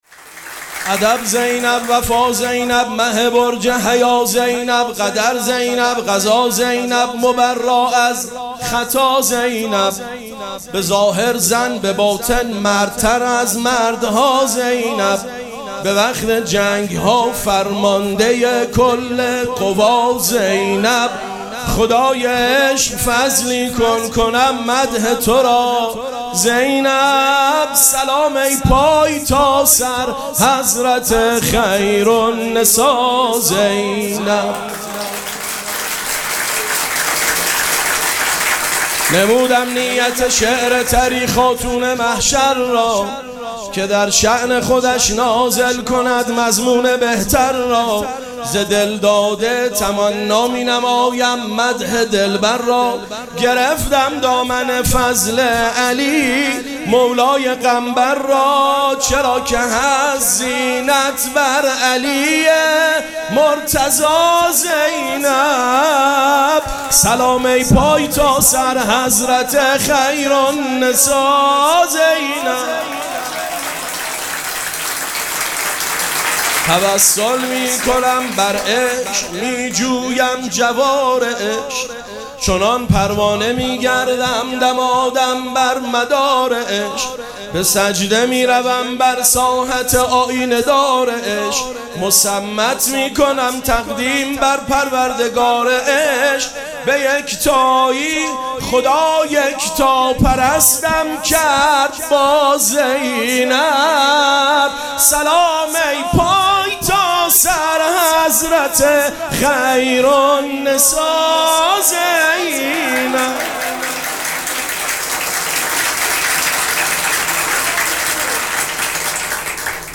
مراسم جشن ولادت حضرت زینب سلام‌الله‌علیها
مدح